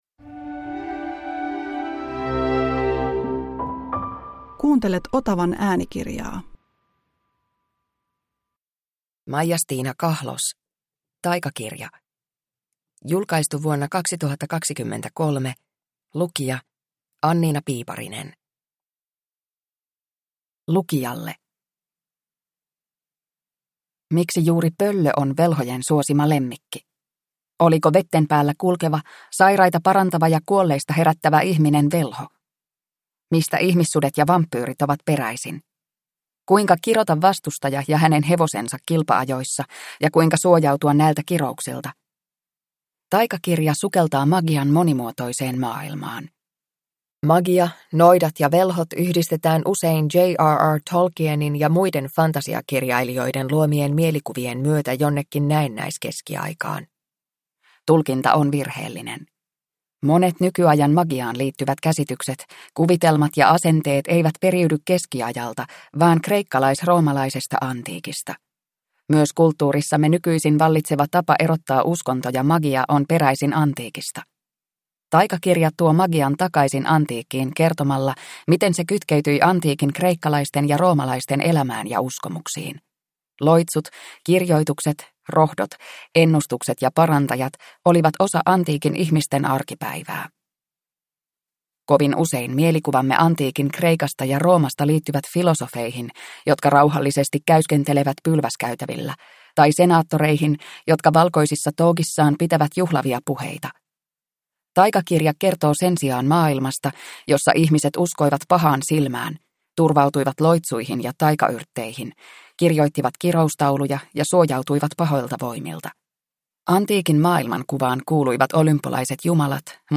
Taikakirja – Ljudbok – Laddas ner